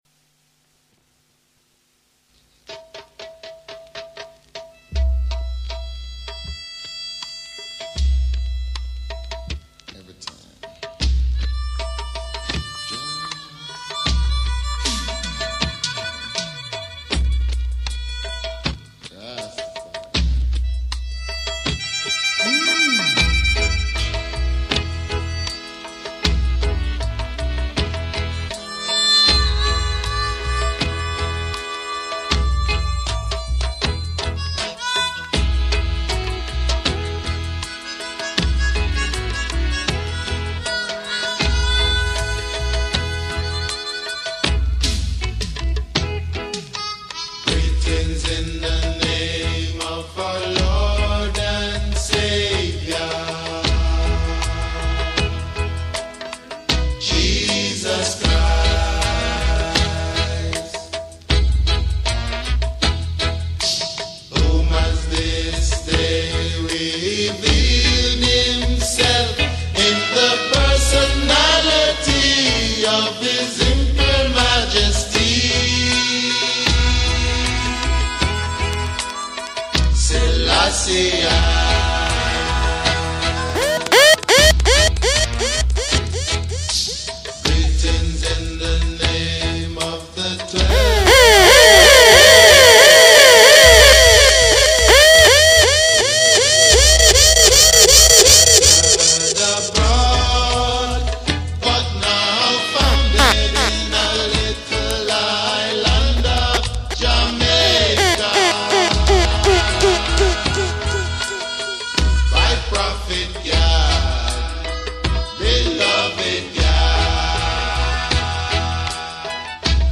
PLAYING RASTA MUSIC UPLIFTING MUSIC